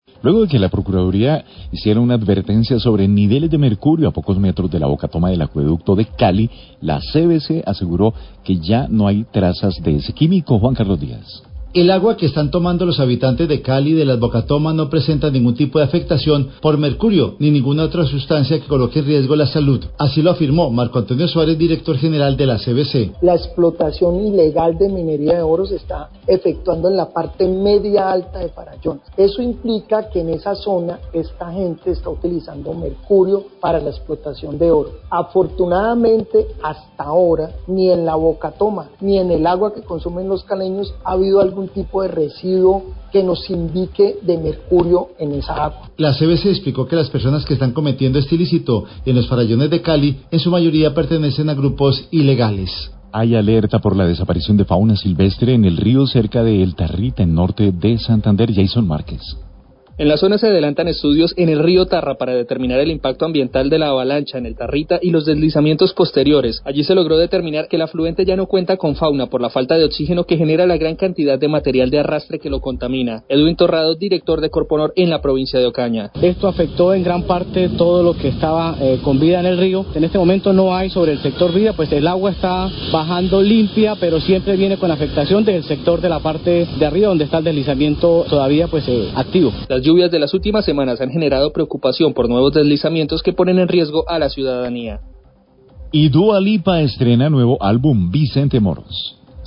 Radio
El director general de la CVC, Marco Antonio Suárez, da un parte de tranquilidad porque, hasta la fecha, no se han detectado trazas de mercurio en las aguas que llegan a la bocatoma del acueducto y que toman los caleños.